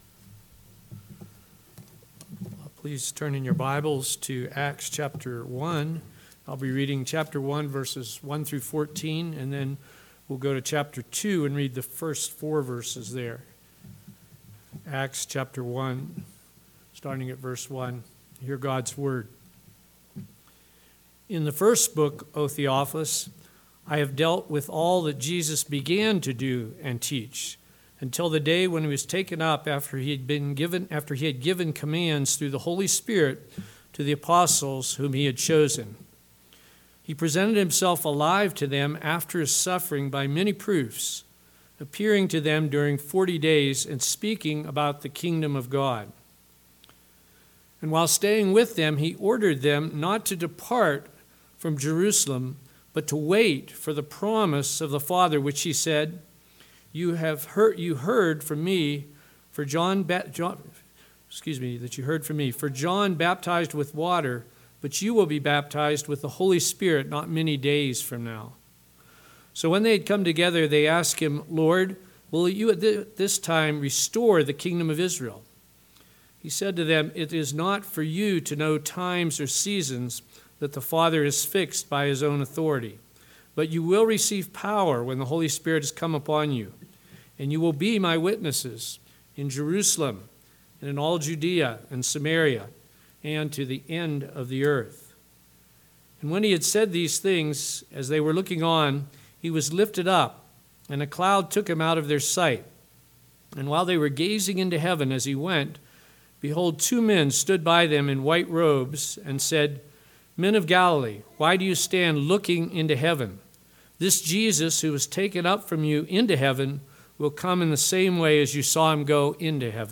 PM Sermon – 9/18/2022 – Acts 1:1-14; 2:1-4 – What Kind of Church Does God Use for His Purposes?